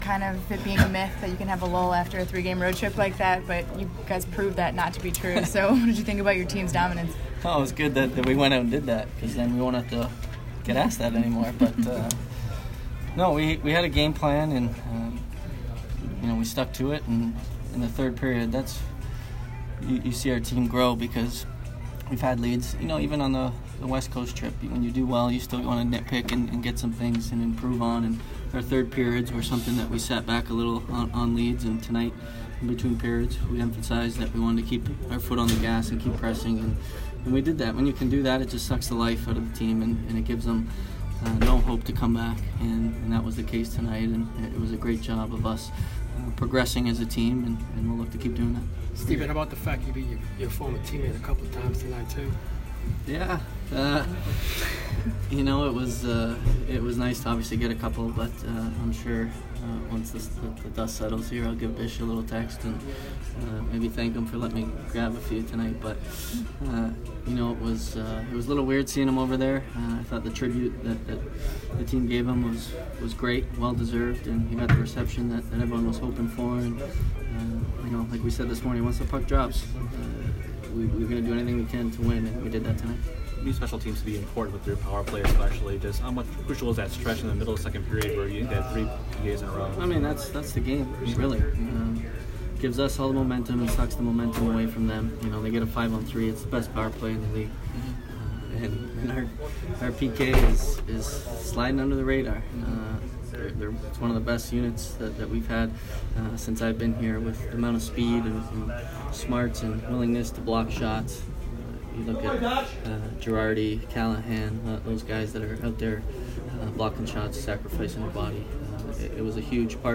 Steven Stamkos Post-Game 11/16